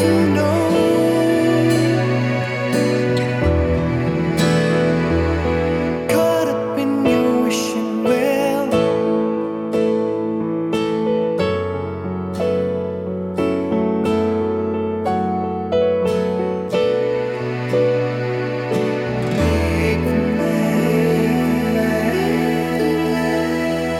With Intro Count In Pop (1990s) 3:41 Buy £1.50